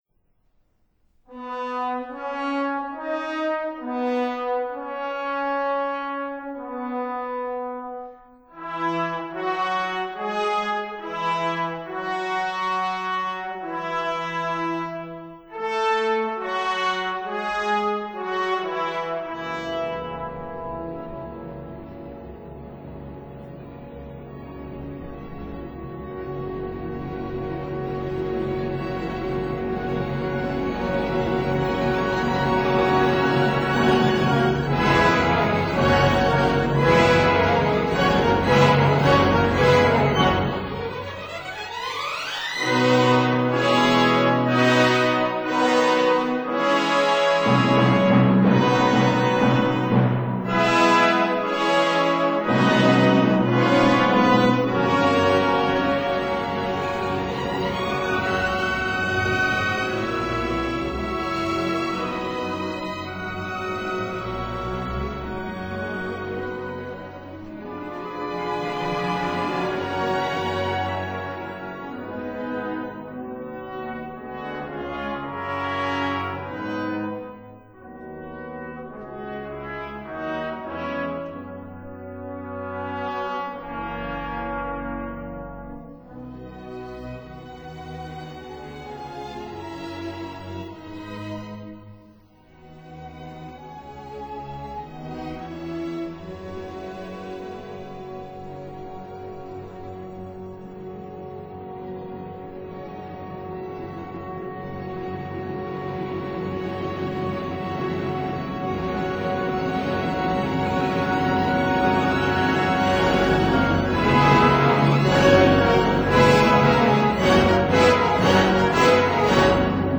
symphonic poem